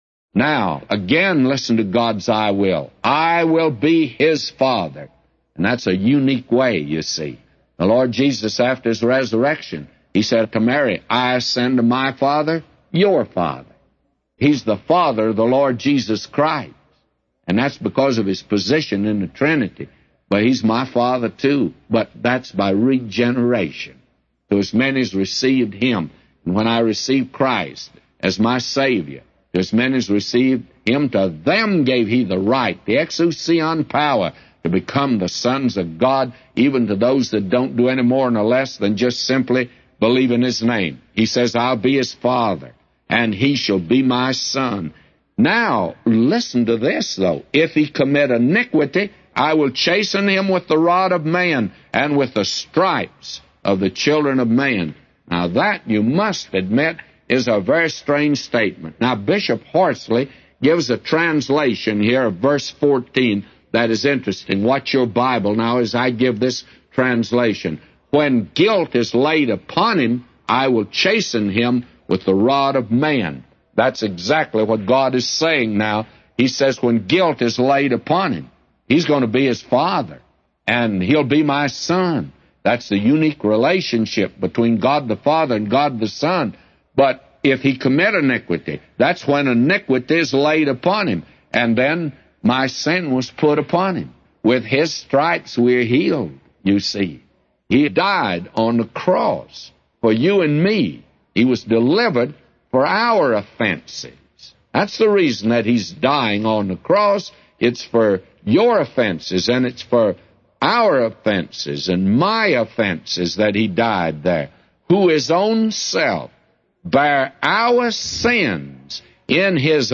A Commentary By J Vernon MCgee For 2 Samuel 7:14-999